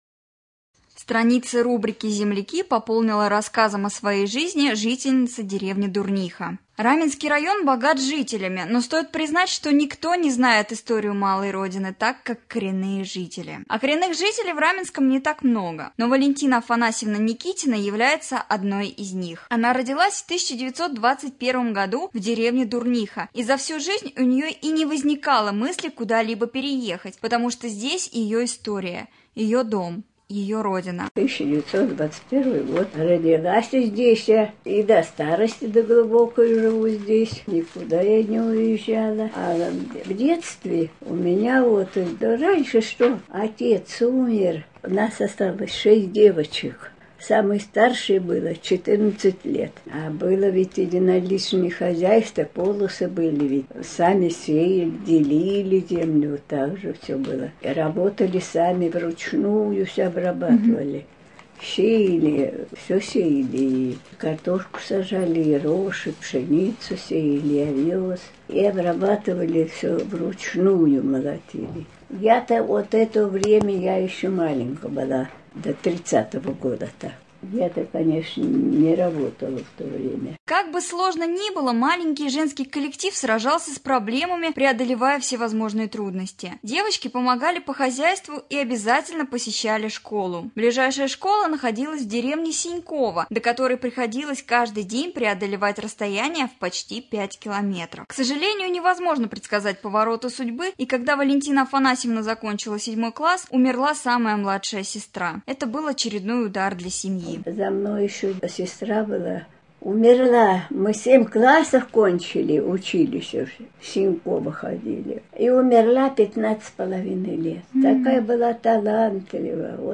1. Новости
3. Рубрика «Актуальное интервью».